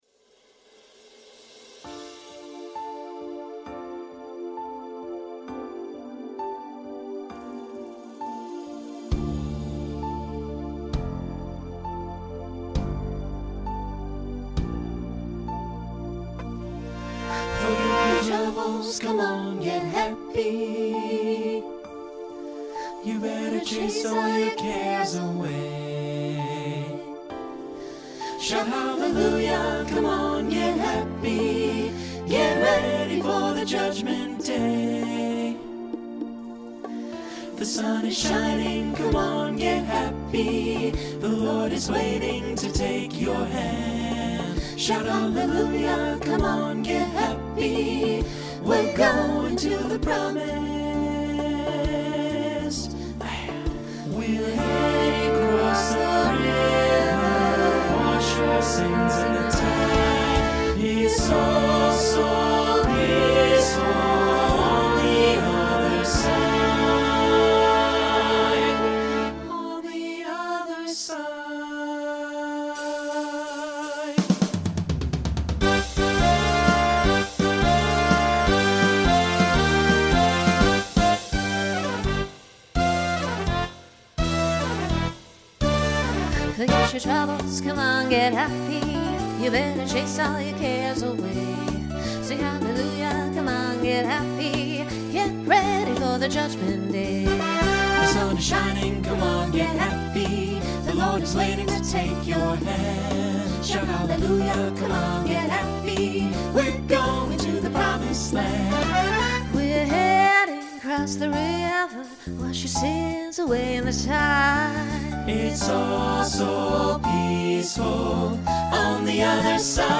Broadway/Film , Swing/Jazz
Function Opener Voicing SATB